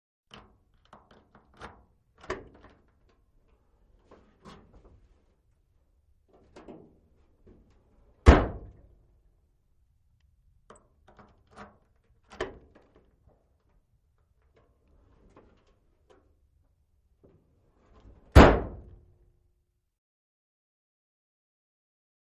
Car Trunk Open / Close ( 2x ); Turn Key In Lock, Pop Trunk Open, Remove Key, Close Trunk. Full-sized Car. Close Perspective.